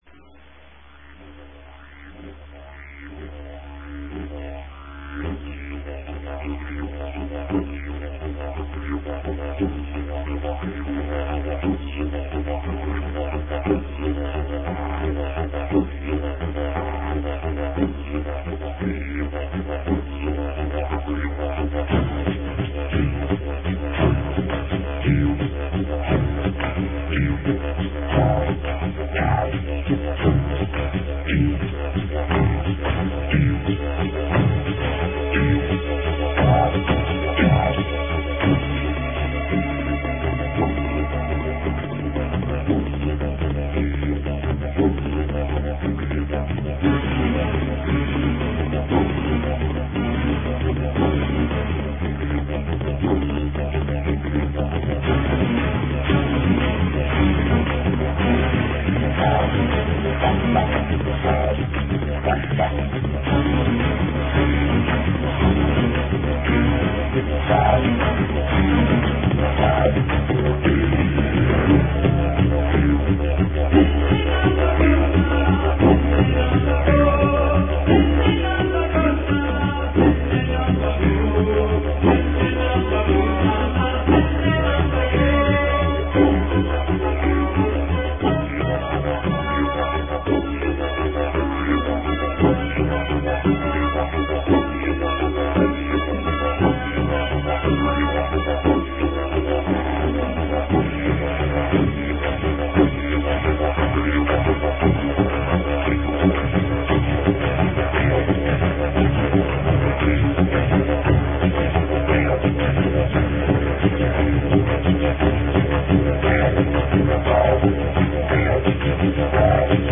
Non-traditional Didjeridu
Traditionaal music rewored in a contemporary form.